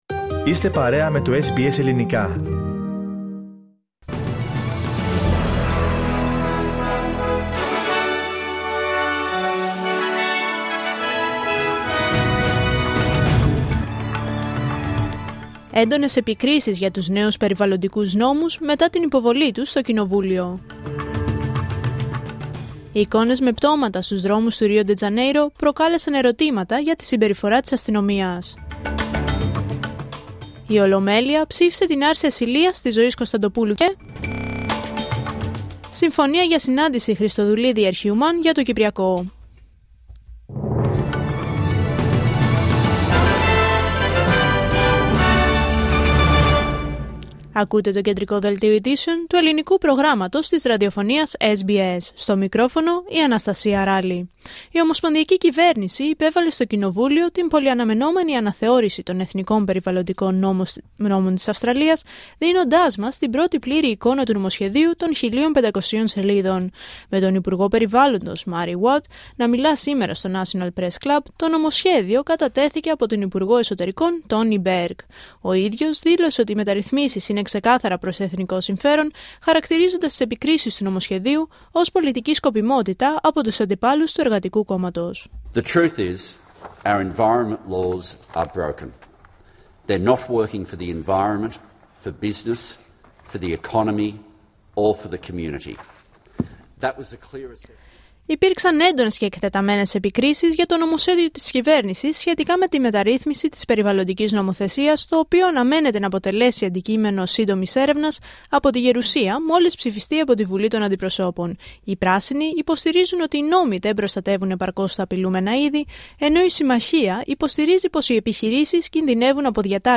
Δελτίο Ειδήσεων Πέμπτη 30 Οκτωβρίου 2025